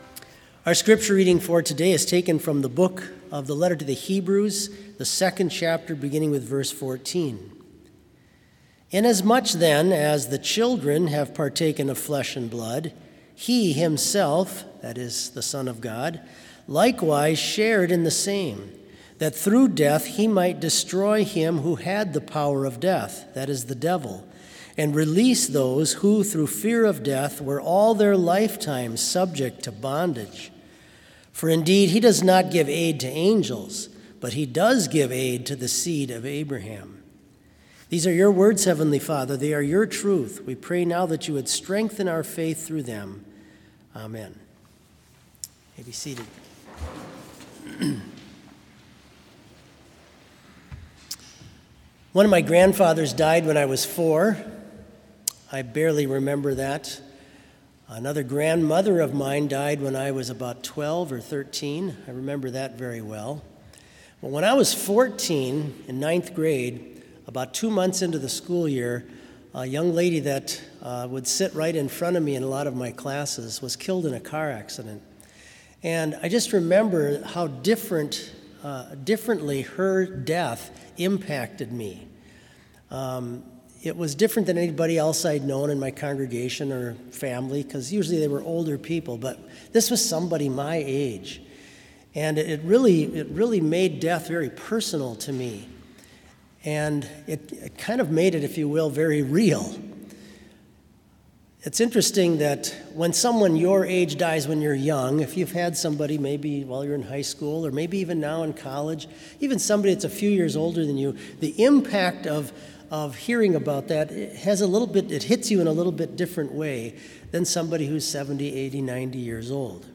Complete service audio for Chapel - December 2, 2020
Sermon